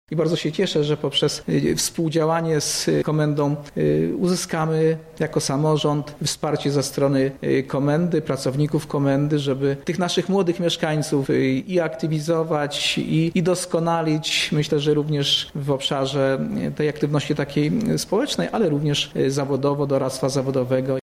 Jak tłumaczy Burmistrz Lubartowa, Janusz Bodziacki, ma ono korzyści dla obydwu storn: